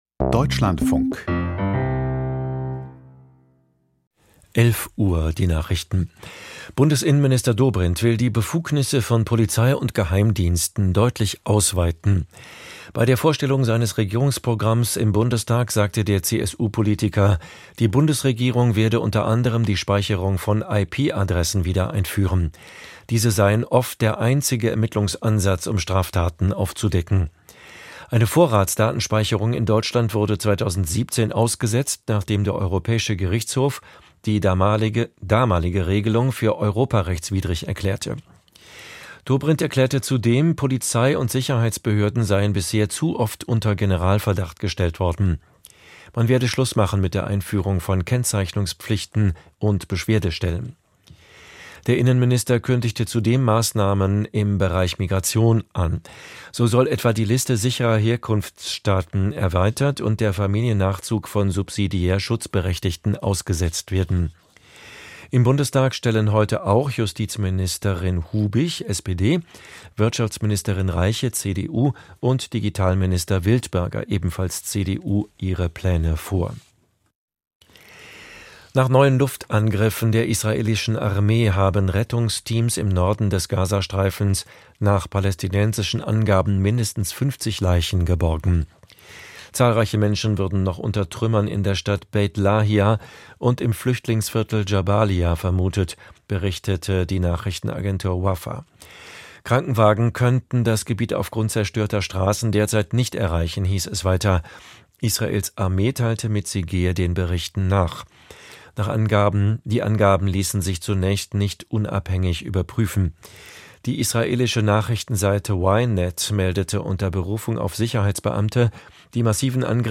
Die Nachrichten vom 16.05.2025, 11:30 Uhr
Aus der Deutschlandfunk-Nachrichtenredaktion.